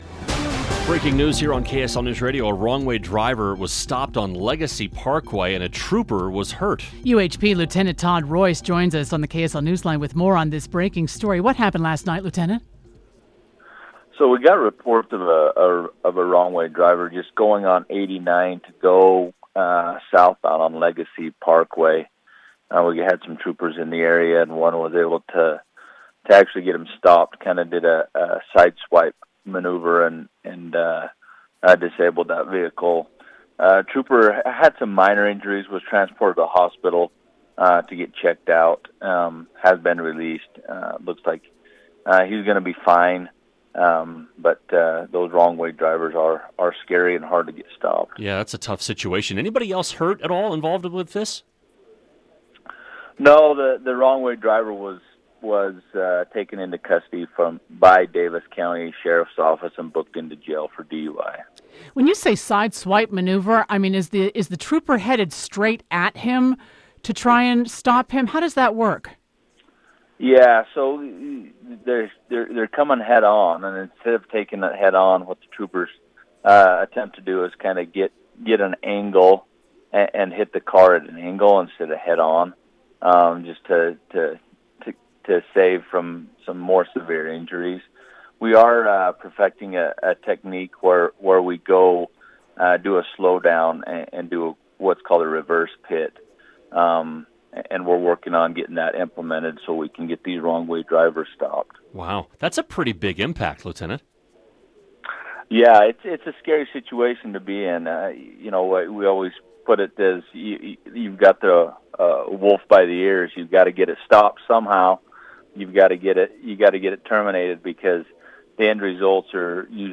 Interview: UHP trooper injured while stopping wrong-way driver